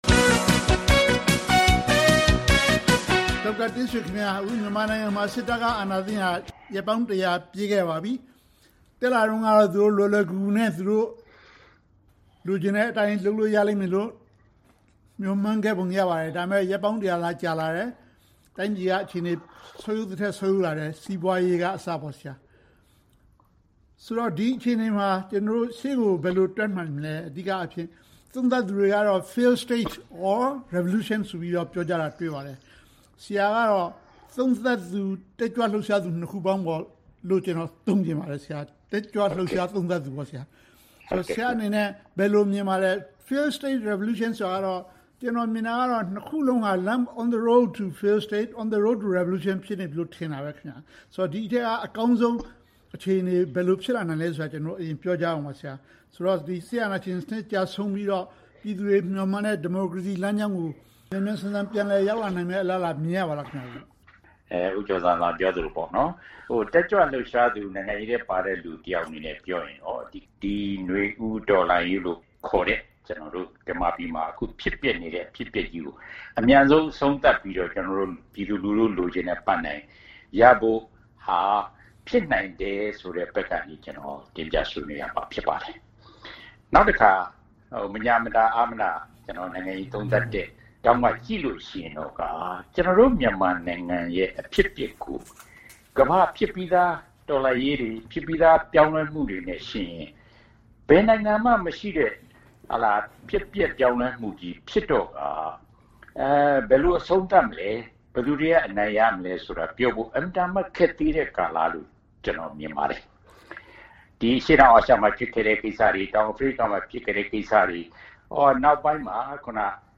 ဆွေးနွေးသုံးသပ်ထားပါတယ်။